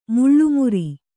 ♪ muḷḷu muri